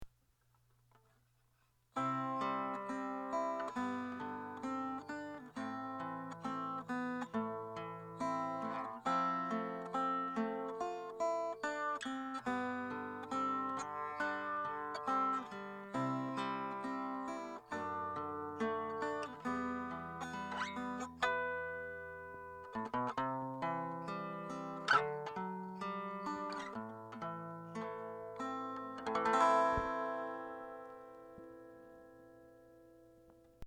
ついでに、よくあるブリッジ下にピエゾのついてる
エレアコのサンプル音源